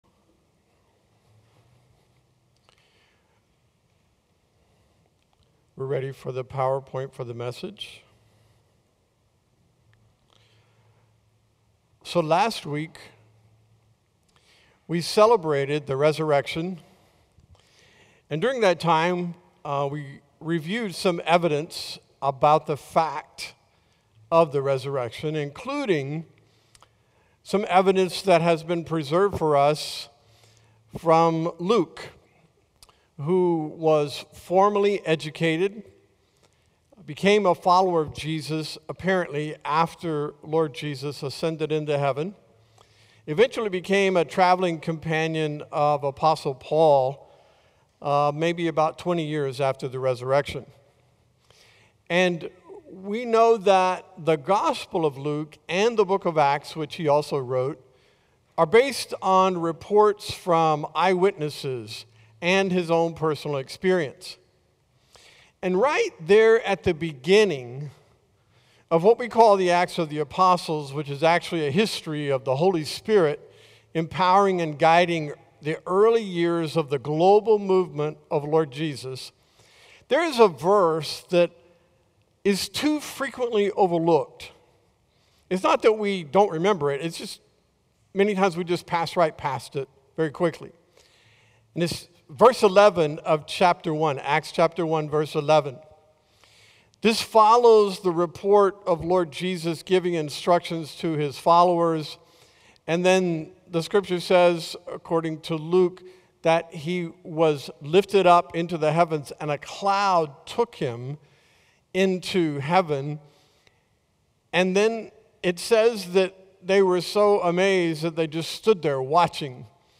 A message from the series "Colossians."